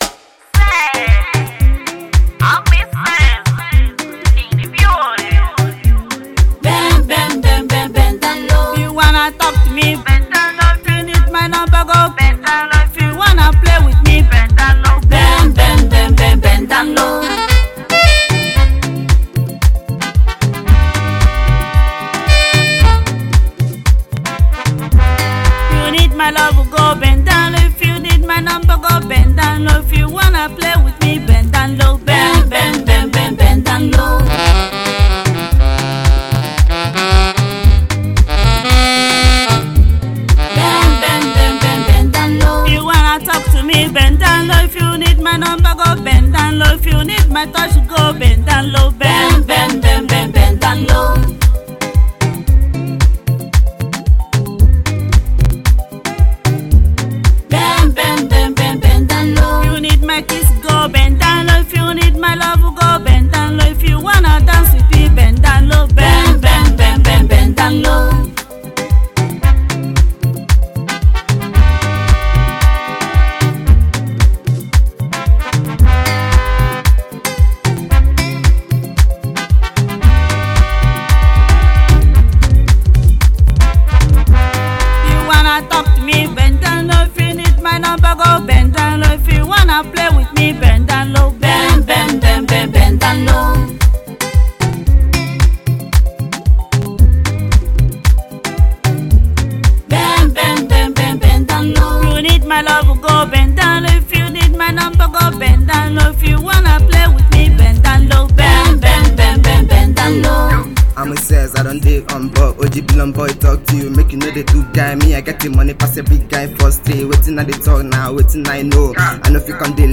a hip hop singer and a gospel singer